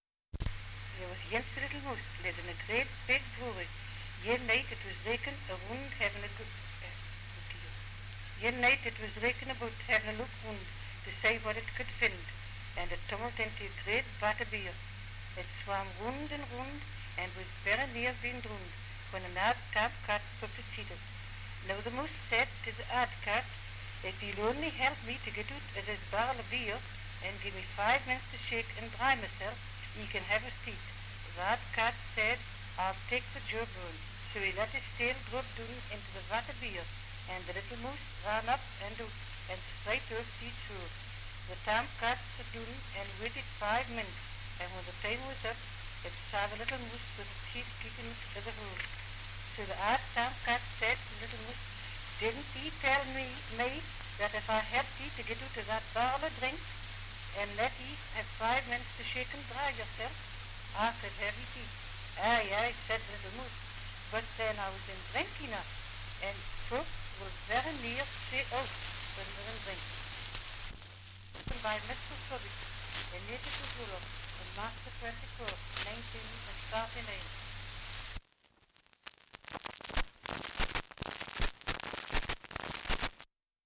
Dialect recording in Northumberland
78 r.p.m., cellulose nitrate on aluminium
English Language - Dialects